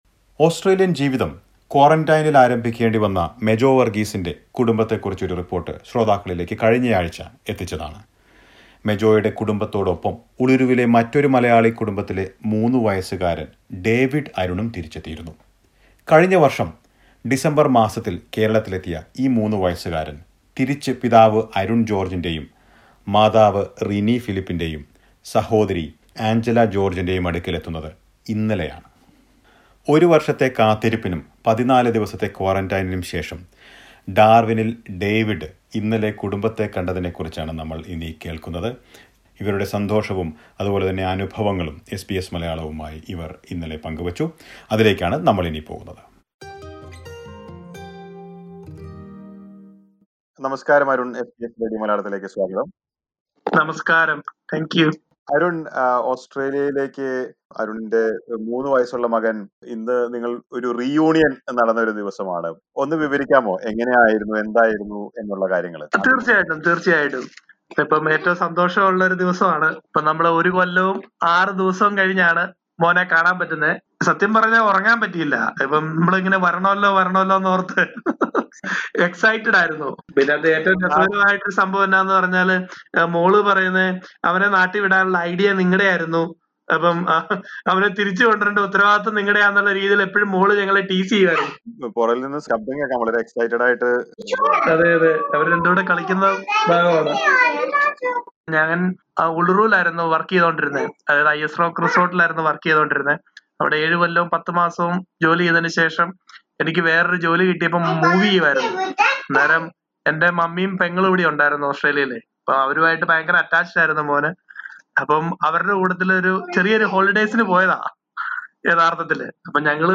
Listen to a report about this reunion.